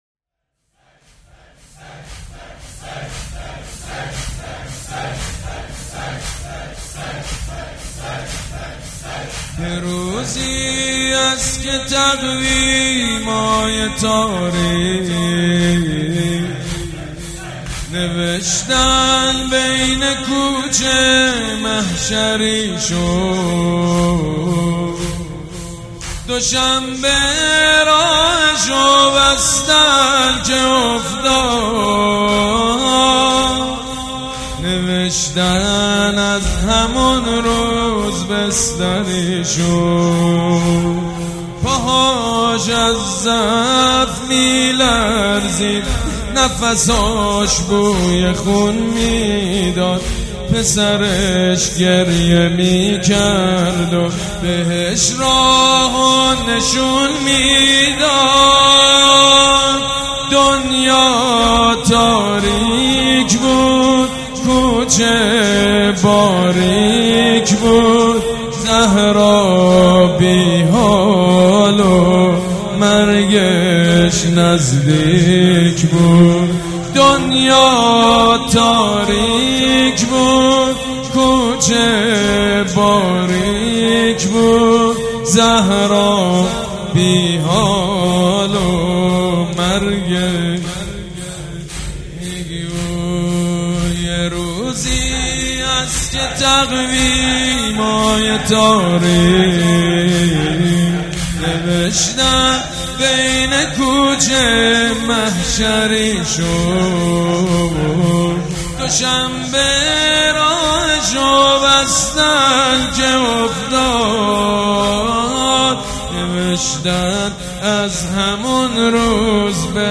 مداح
حاج سید مجید بنی فاطمه
شهادت امام صادق (ع)